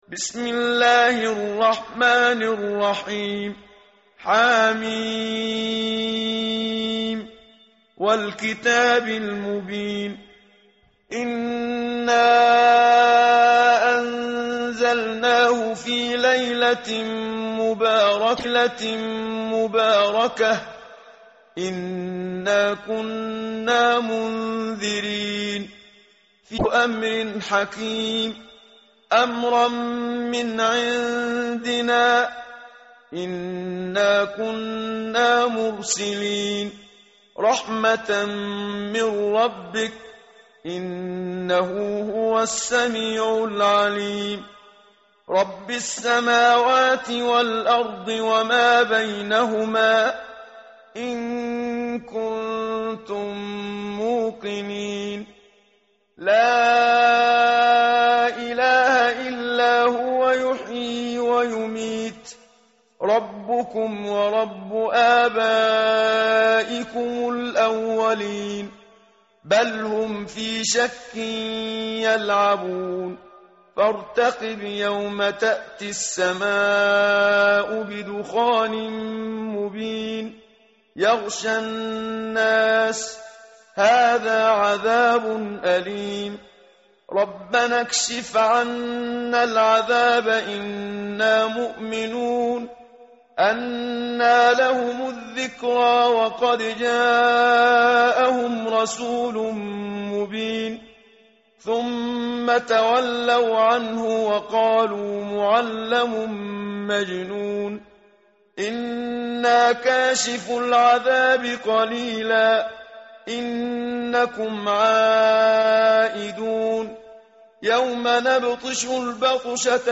متن قرآن همراه باتلاوت قرآن و ترجمه
tartil_menshavi_page_496.mp3